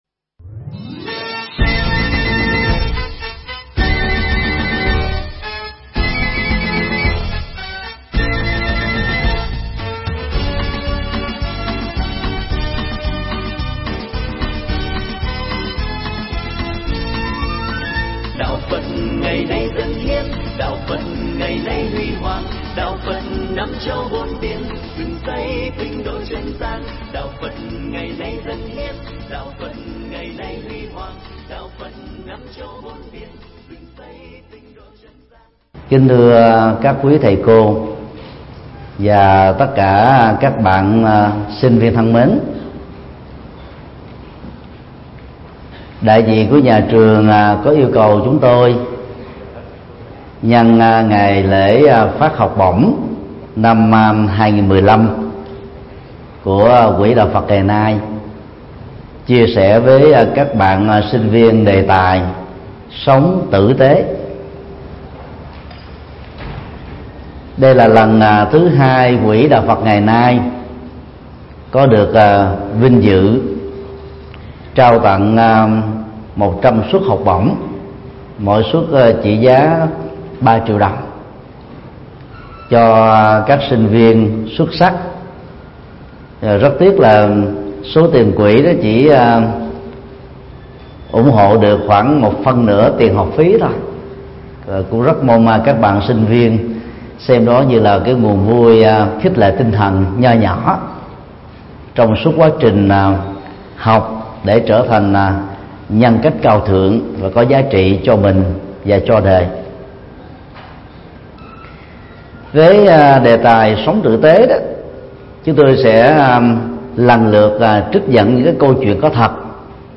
Pháp âm Sống tử tế – Do Thầy Thích Nhật Từ Thuyết Giảng
Mp3 Thuyết Giảng Sống tử tế – Thầy Thích Nhật Từ Giảng tại Trường Đại học Khoa học Xã hội và Nhân văn, ngày 1 tháng 12 năm 2015